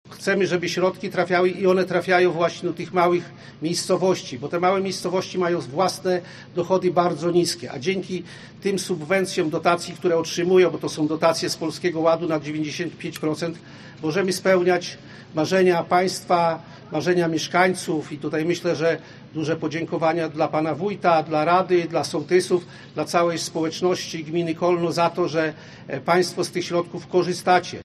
Poseł Prawa i Sprawiedliwości, Kazimierz Gwiazdowski dodał, że rząd pomaga tym, którzy najbardziej potrzebują wsparcia: